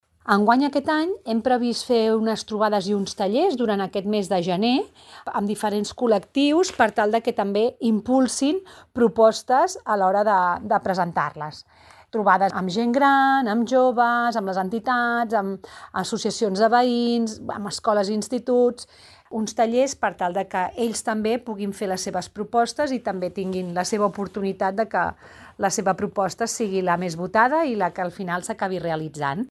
Àngels Soria, regidora de Teixit Associatiu, Cooperació i Internacionalització